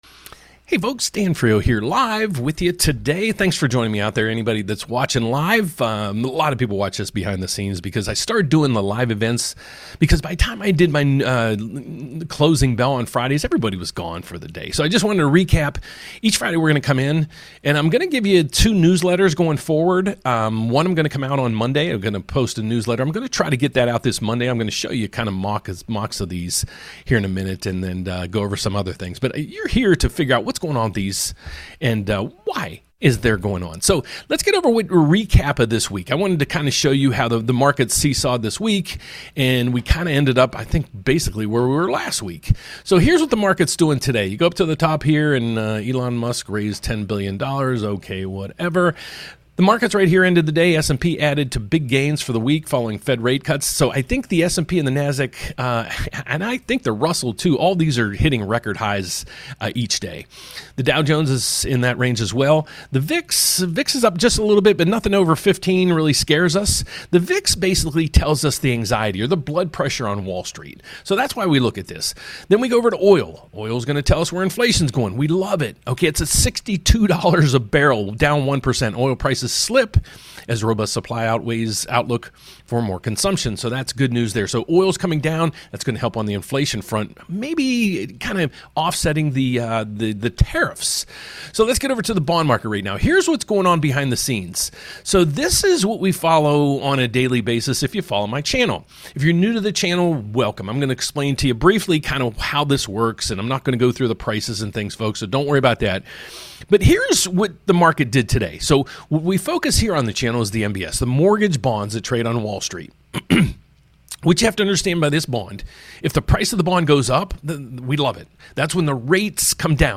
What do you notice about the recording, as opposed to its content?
Friday Rate Update LIVE 🏠 Mortgage Recap + Next Week’s Outlook